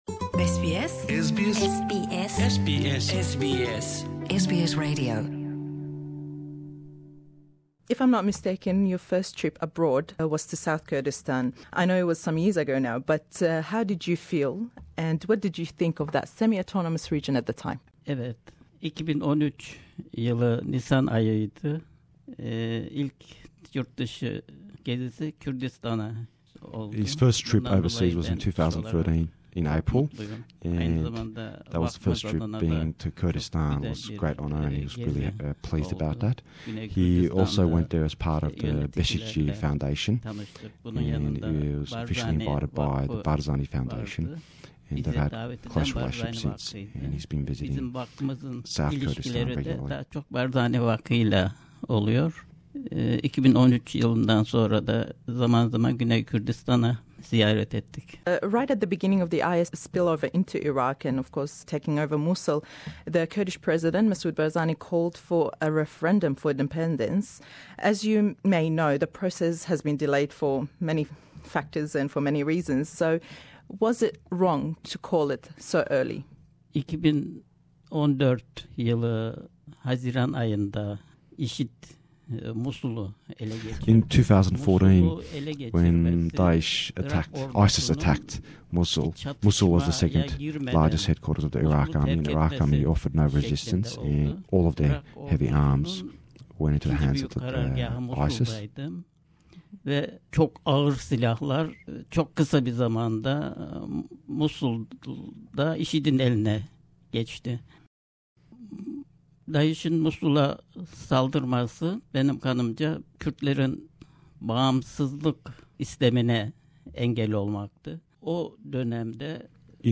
le em hevpeyvîne da ke wergêrawe be Înglîzî le zimanî Turkî ye we, ême le Mamoste Bêşikçî- çend prisiyarêk dekeyn sebaret be yekem geshtî bo derewey Turkiya ke bo Başûrî Kurdistan bû le sallî 2013 de da, ke ew pêmanî rageyand zor dill xoş bû be ew geşte mêjûî ye.